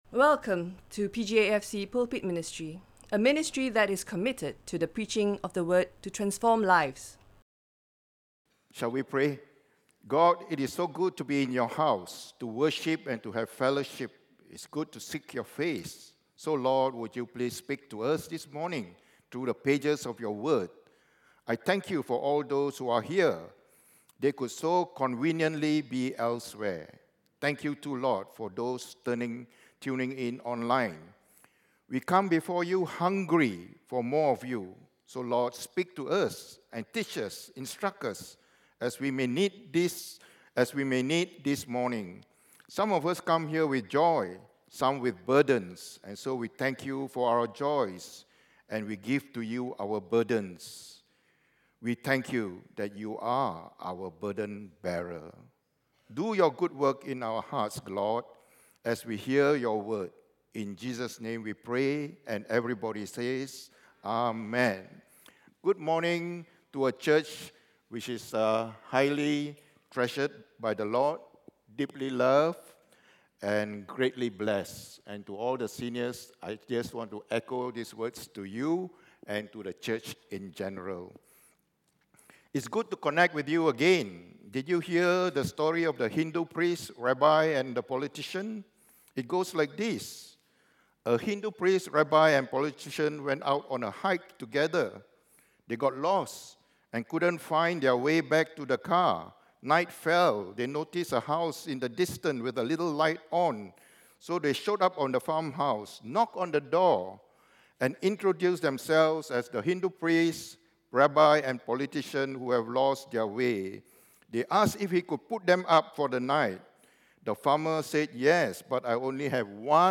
In conjunction with World Senior Day, this is a stand alone sermon.
Listen to Sermon Only